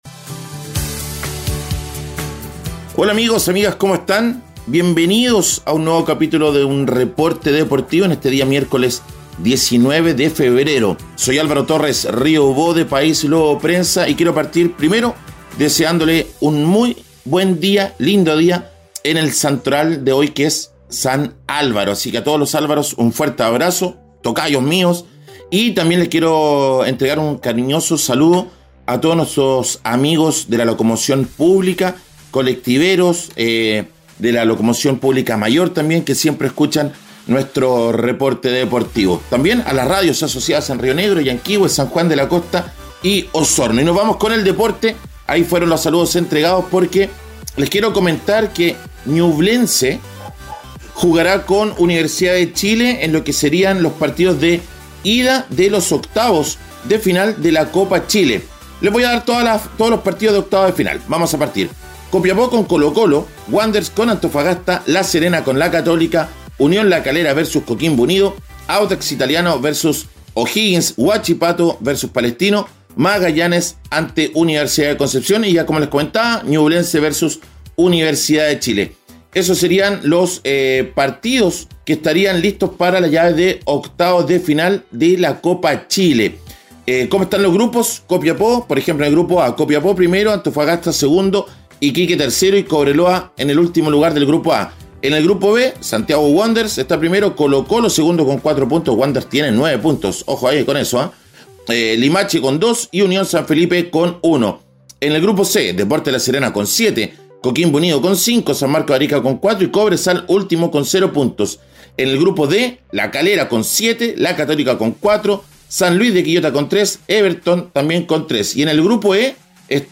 Reporte Deportivo 🎙 Podcast 19 de febrero de 2025